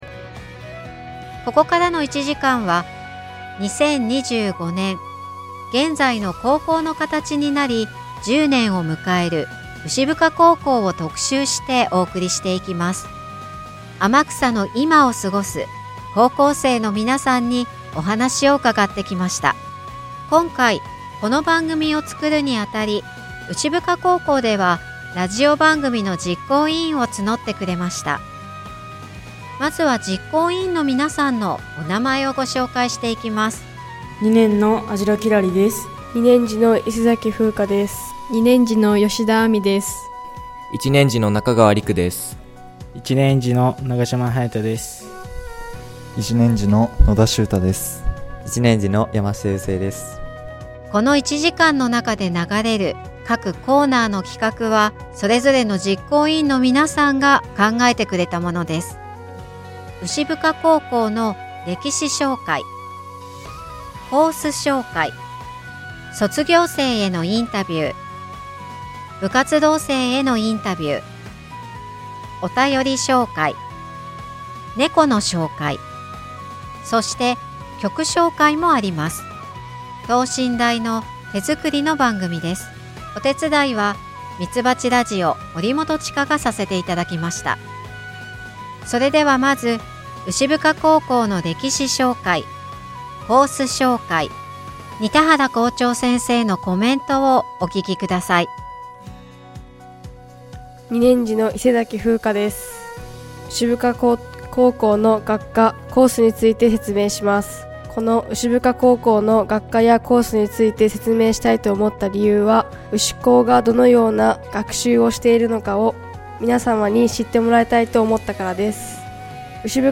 先月末にみつばちラジオで放送された「牛深高校創立１０周年記念番組」の音源が届きました！
なお、著作権の関係でアーティストの音楽はカットされています。